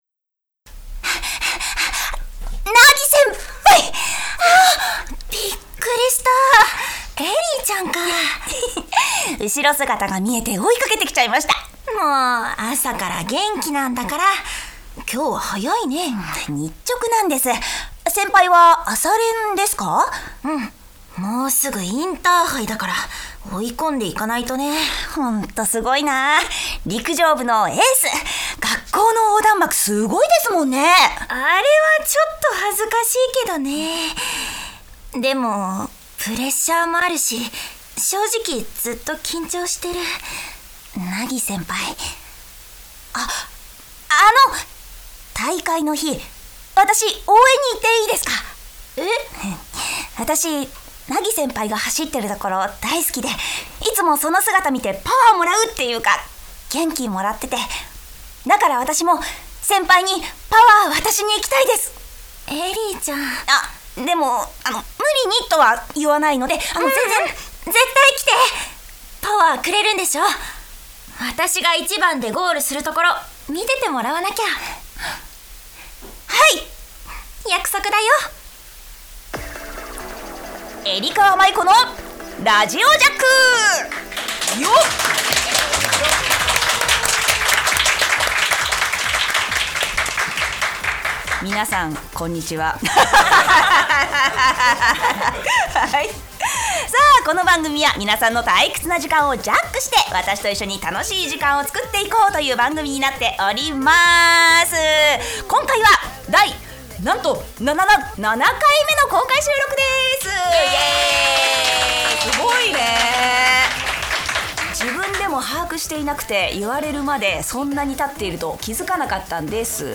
な・な・なんと！7回目の公開収録です！！